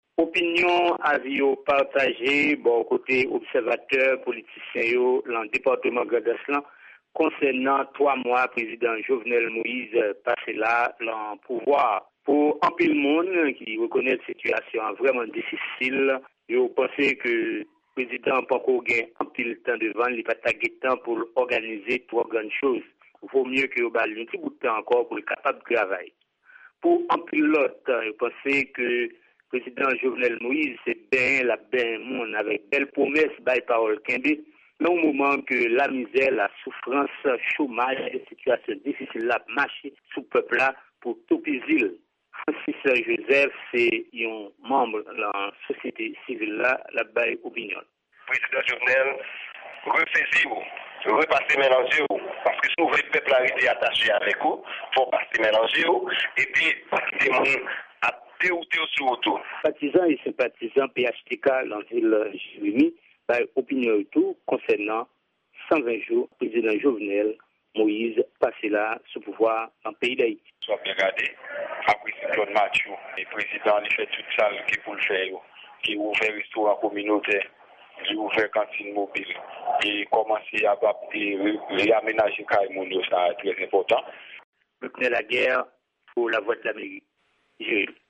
Manm sosyete sivil la ak politisyen yo nan Depatman Grandans la gen opinyon diferan sou bilan plis pase 100 jou Prezidan Jovenel Moise sou pouvwa a. Anpil nan sitwayen sa yo di Lwadlamerik peryòd 120 jou pa sifi pou fè yon evalyasyon sou pèfòmans administrasyon Moise la. Repòtaj